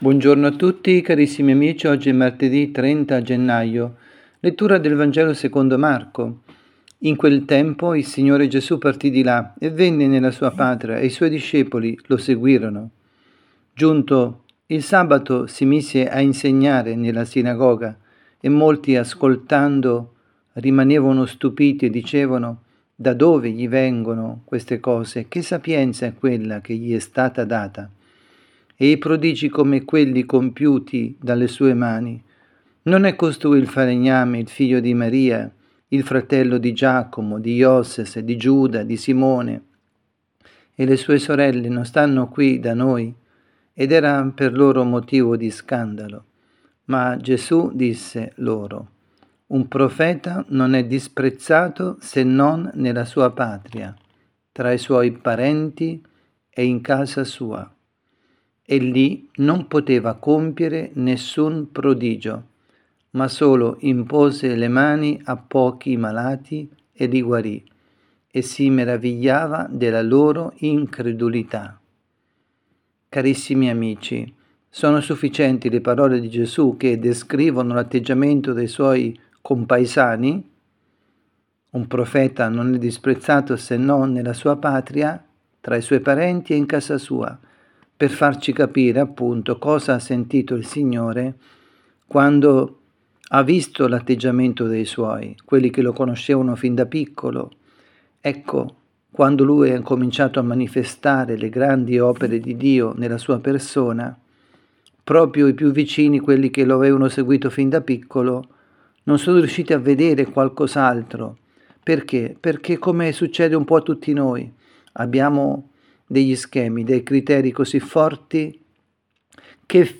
Catechesi
dalla Parrocchia Santa Rita – Milano